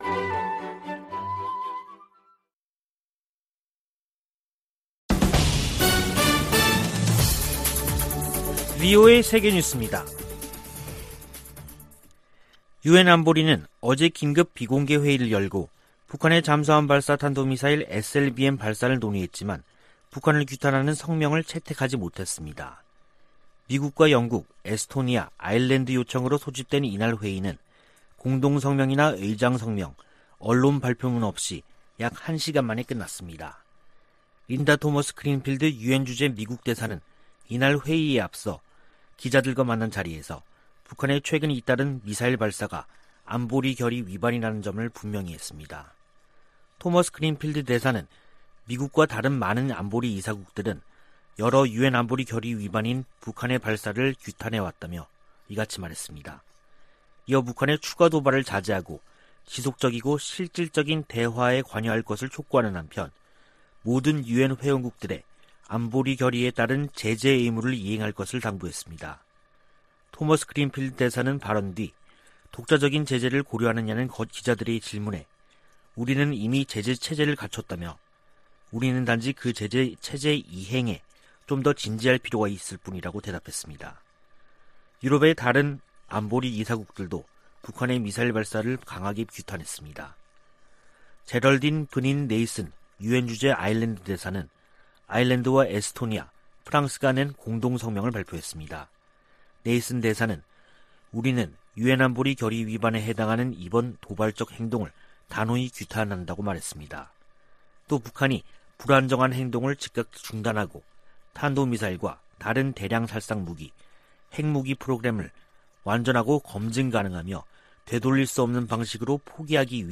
VOA 한국어 간판 뉴스 프로그램 '뉴스 투데이', 2021년 10월 21일 2부 방송입니다. 유엔 안보리가 북한 SLBM 규탄 성명을 내는데 실패했습니다. 독일과 영국이 북한의 미사일 시험 발사를 규탄하며, 완전하고 검증 가능하며 되돌릴 수 없는 핵포기를 촉구했습니다. 북한은 SLBM 시험발사가 미국을 겨냥한 게 아니라고 주장했습니다.